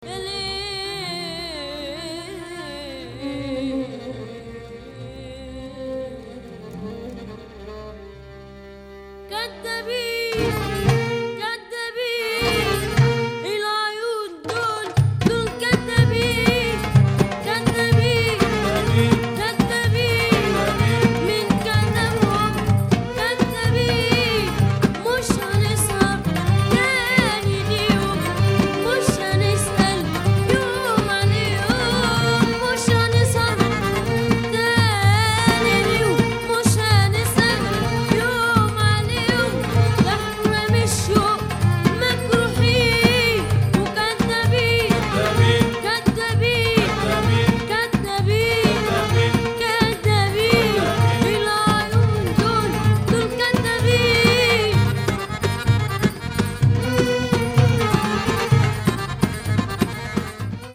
gypsy brass